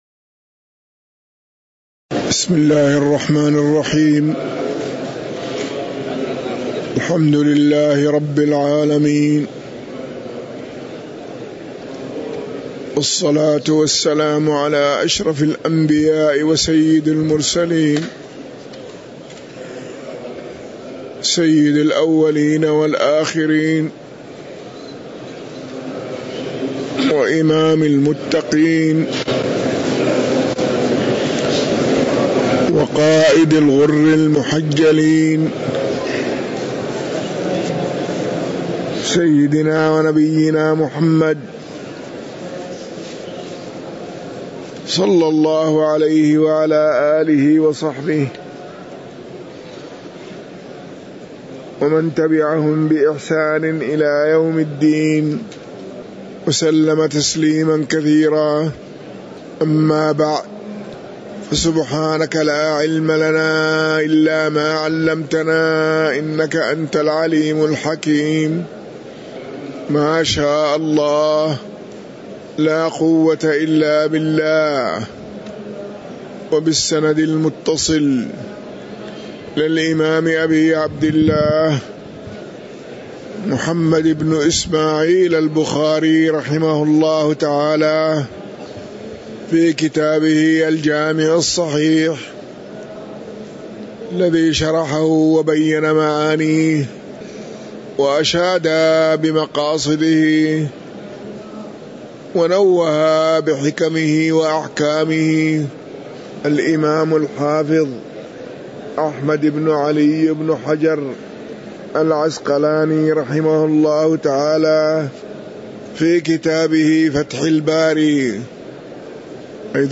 تاريخ النشر ١٧ شوال ١٤٤٣ هـ المكان: المسجد النبوي الشيخ